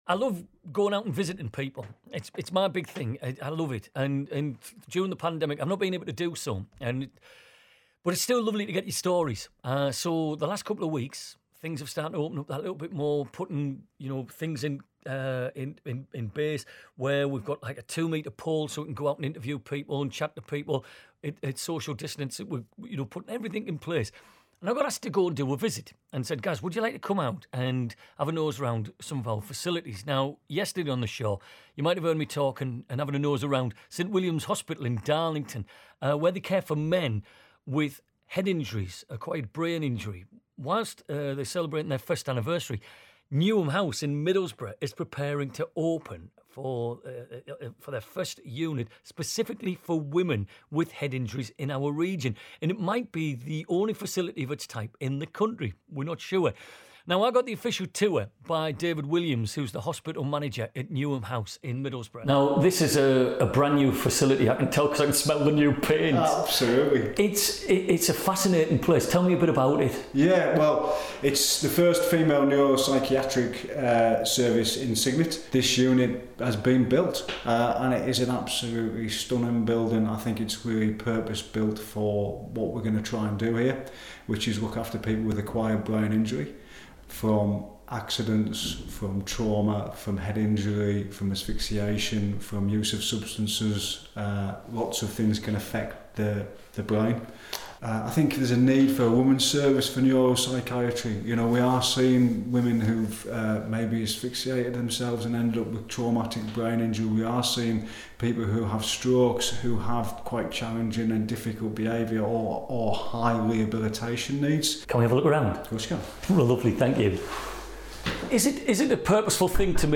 Newham-Interview-1.mp3